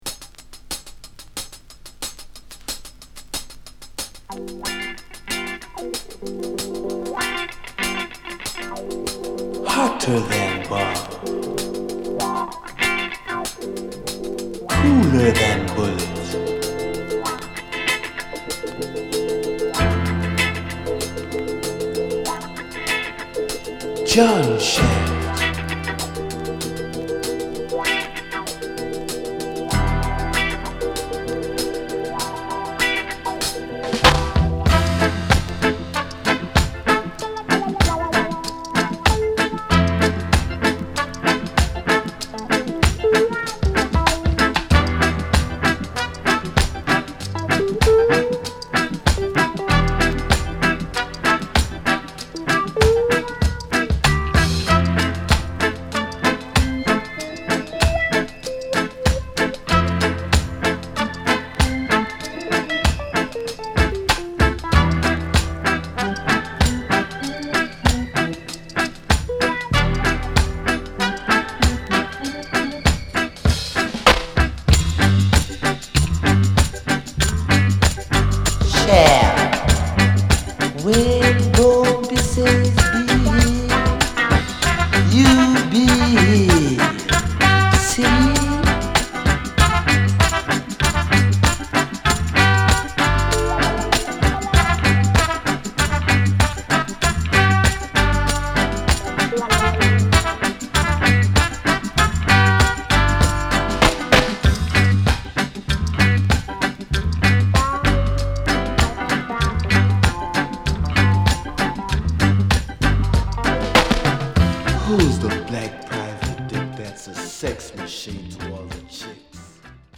をミディアムテンポのアレンジでファンキーレゲエカヴァー！